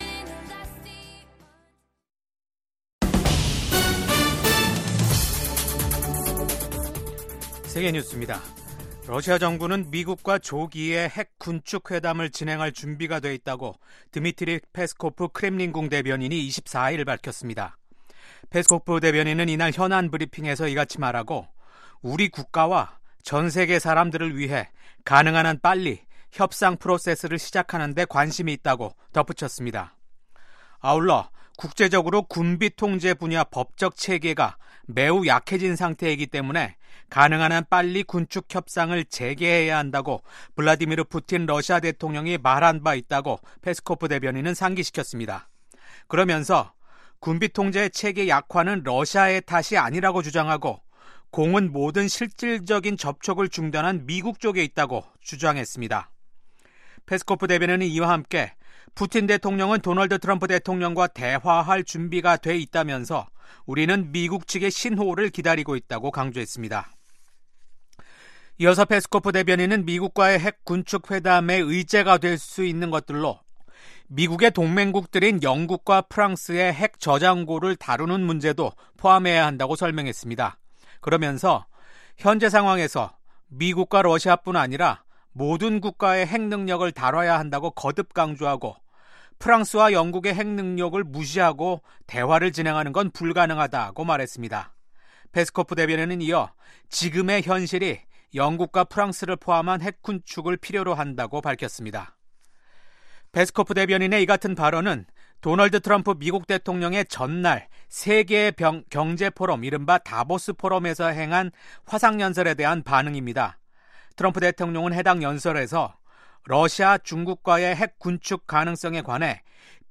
VOA 한국어 아침 뉴스 프로그램 '워싱턴 뉴스 광장'입니다. 도널드 트럼프 미국 대통령이 김정은 북한 국무위원장과 다시 만날 것이라는 의지를 밝혔습니다. 미국 전문가들은 미북 정상회담이 열릴 경우 북한 안전 보장과 대북 제재 해제를 대가로 추가 핵 실험과 미사일 발사 중단 등 북한 핵 동결이 논의될 수 있을 것으로 전망했습니다. 북한은 이틀간 최고인민회의를 열었지만 미국과 한국에 대한 메시지를 내놓지 않았습니다.